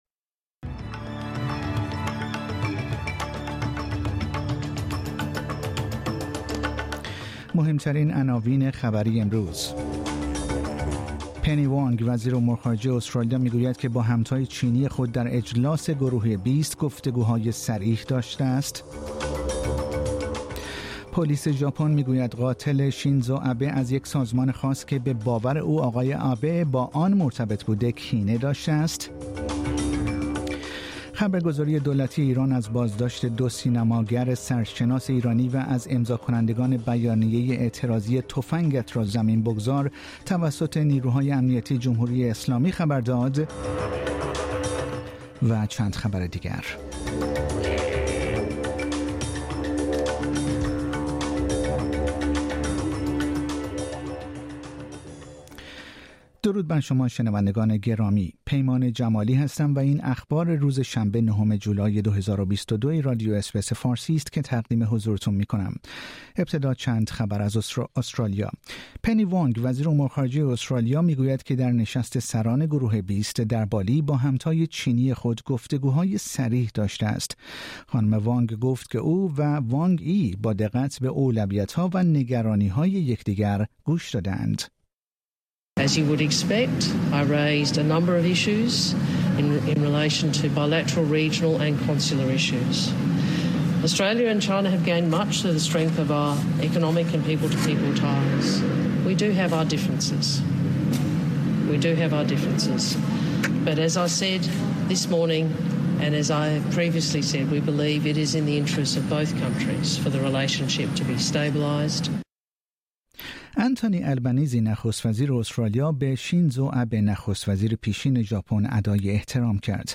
پادکست خبری آخر هفته اس بی اس فارسی